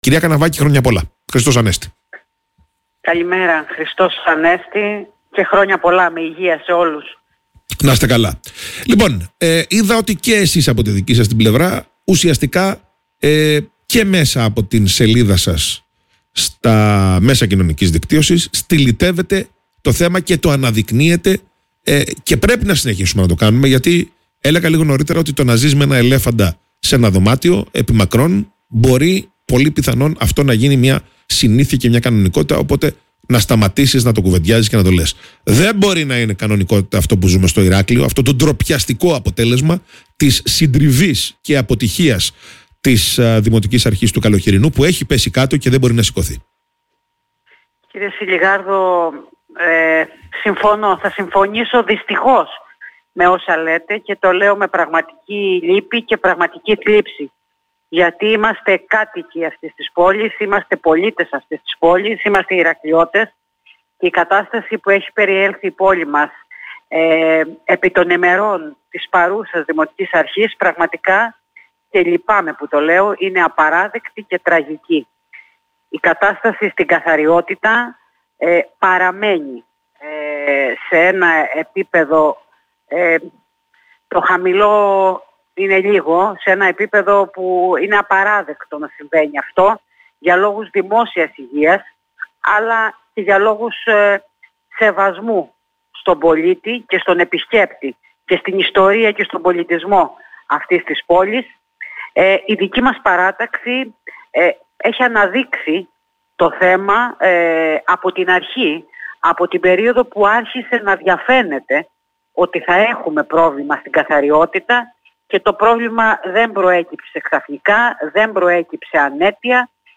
μιλώντας στην ραδιοφωνική εκπομπή ΠΥΞΙΔΑ